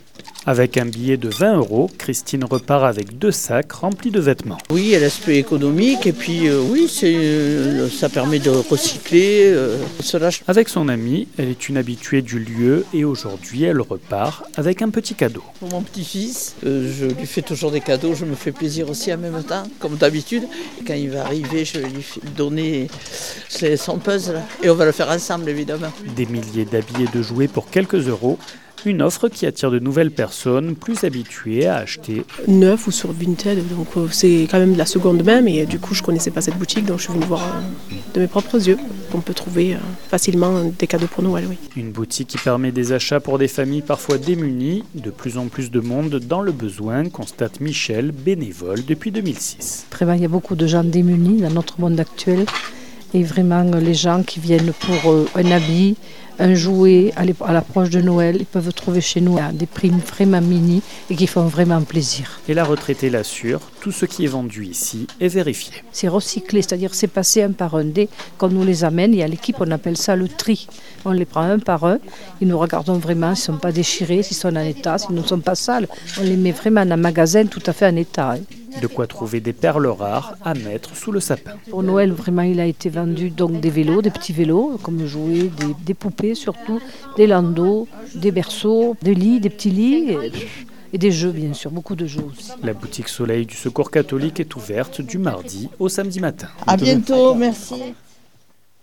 Qu’en est-il en Lozère ? 48FM est allé vérifier à Mende, à la boutik’soleil du Secours catholique.
Reportage